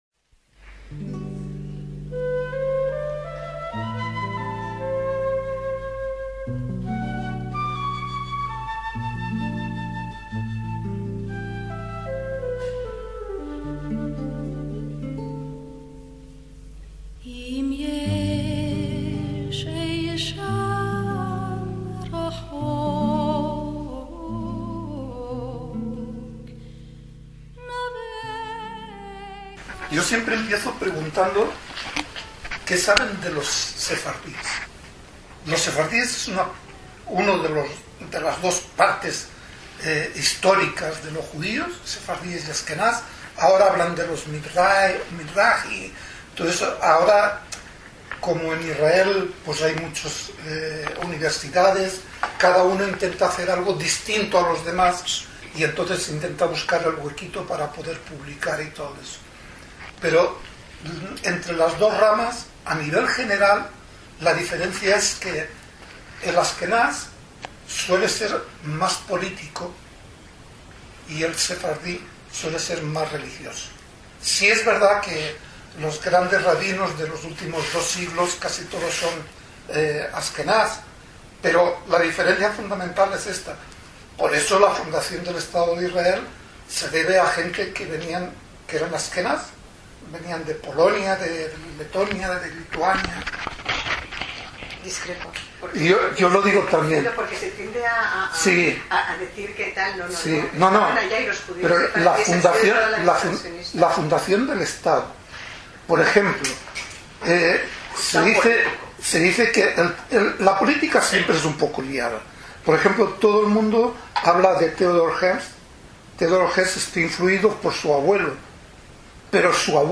EL REPORTAJE - Visitamos la biblioteca Moshe Shem Tov de León en Madrid, un tesoro que deposita 3.000 libros y documentacion de la historia y cultura de los sefardies en España y en el mundo.